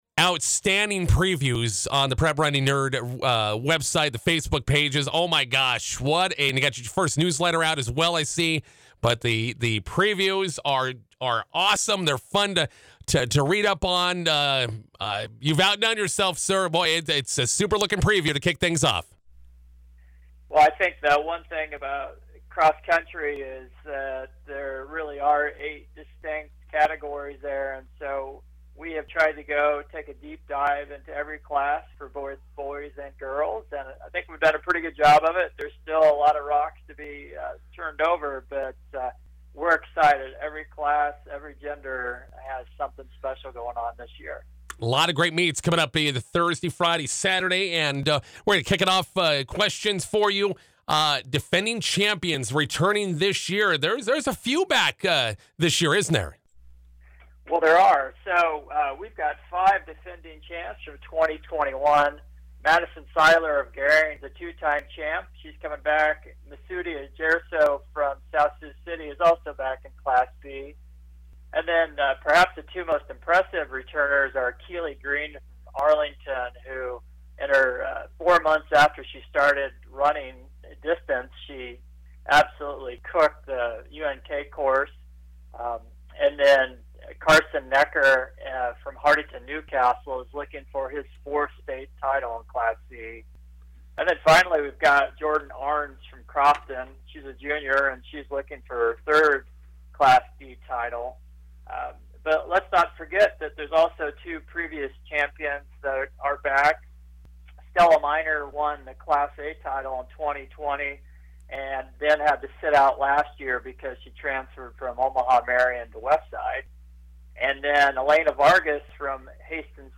McCook radio interview
We connected during the track season and our plan is to talk cross country every two or three weeks this fall during the cross country season. Here's our first interview of the fall.